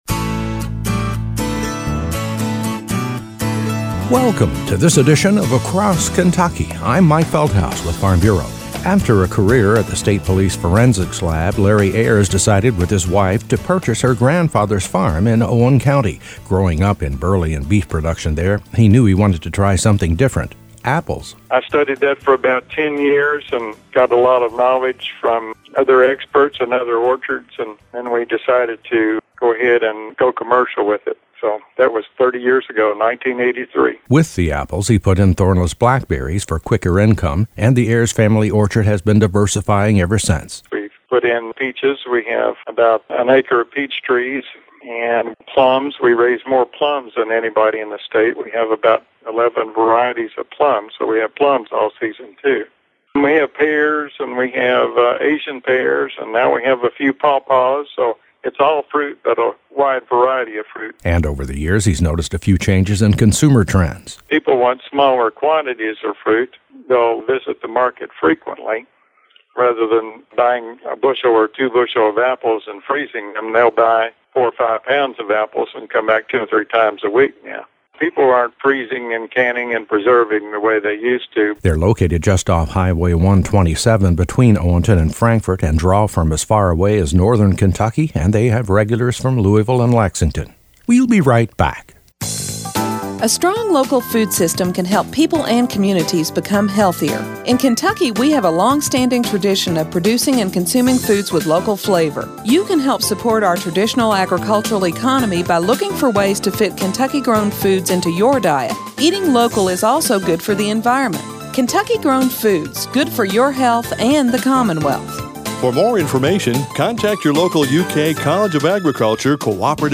Agritourism